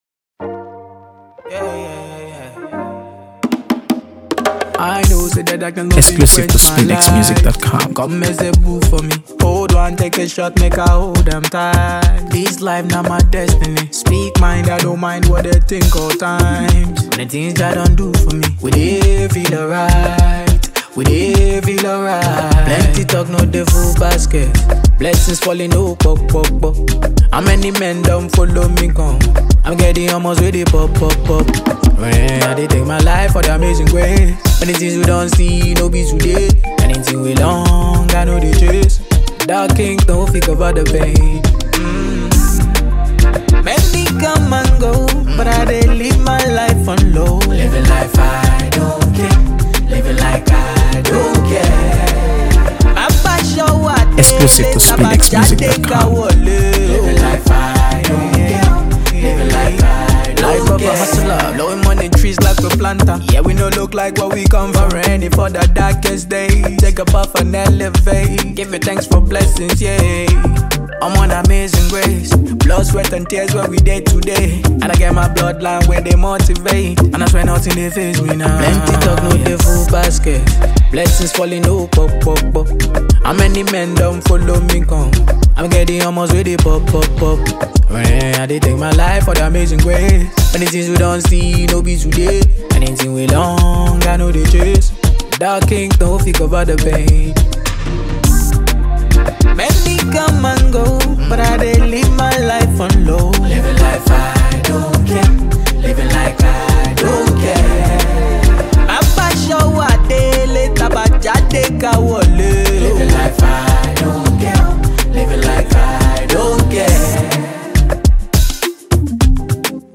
AfroBeats | AfroBeats songs
Afrobeat rhythms and heartfelt lyrics